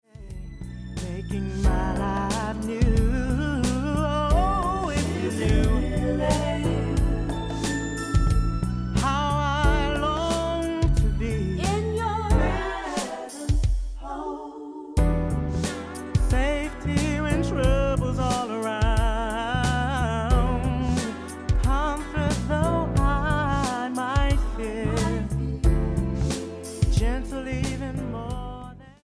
STYLE: R&B
a mellow groove